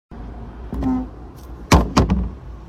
Closing Trash Can